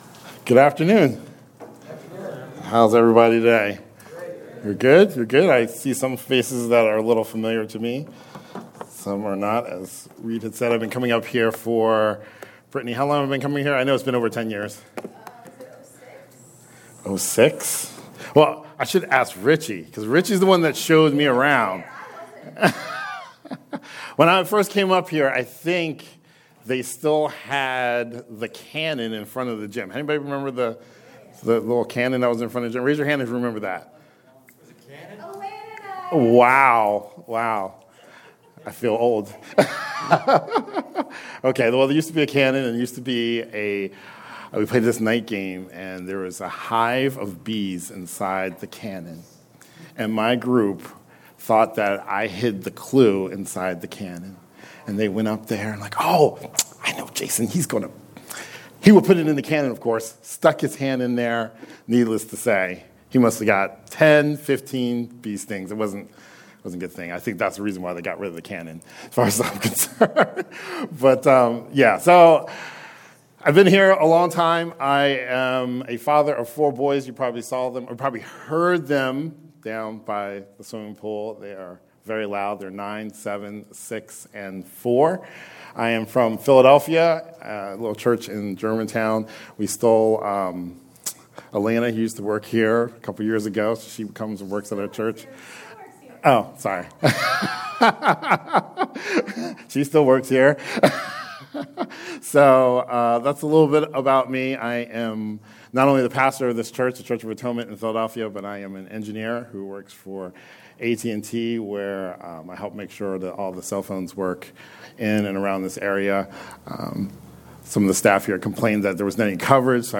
A biblical exhortation for the Rock Mountain Summer Staff on July 2, 2020.